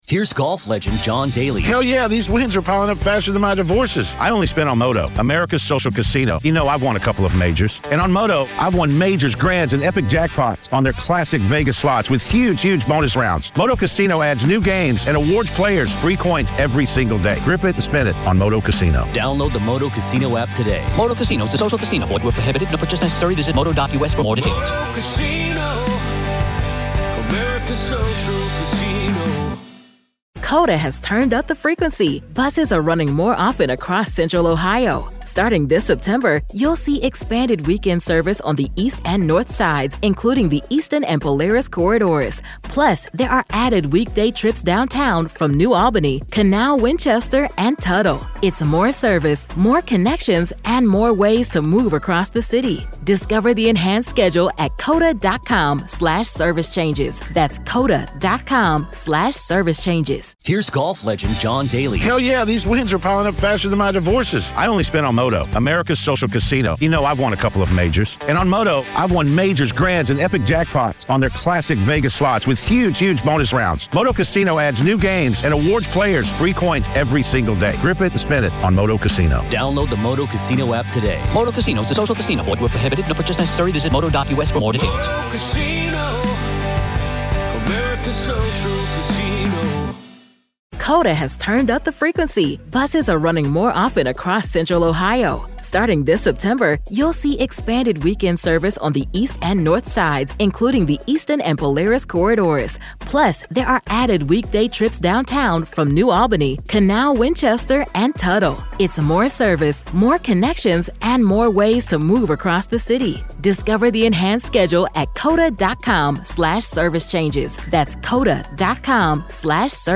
One conversation that lays out the stakes, the law, and the fallout.